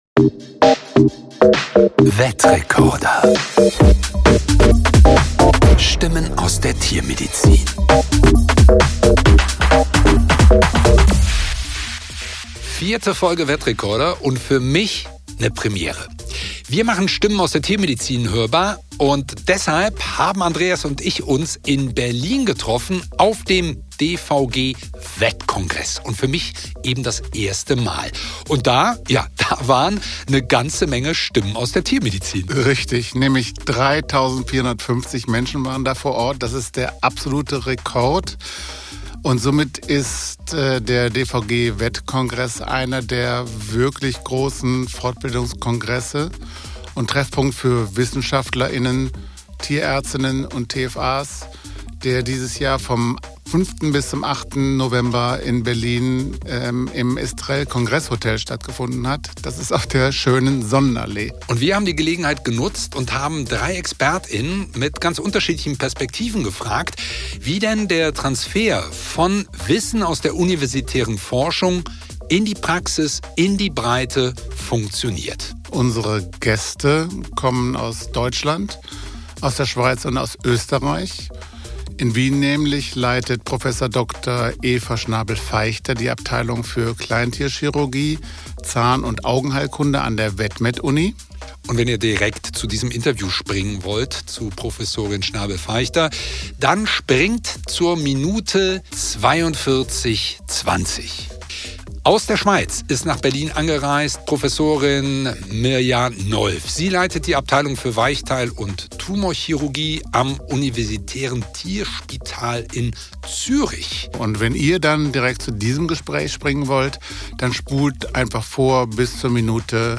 Wir diskutieren, wie Wissen übersetzt, vermittelt und in den klinischen Alltag integriert wird – von chirurgischer Evidenz über Fortbildungsformate bis hin zu modernen Technologien wie Bildgebung und KI. Die Episode zeigt, warum Wissenstransfer kein theoretischer Prozess, sondern gelebte Zusammenarbeit zwischen Forschung, Klinik und Praxis ist. Der DVG-Vet-Congress, auf dem wir die drei Interviews führen durften, findet einmal jährlich im Berliner Estrel Hotel statt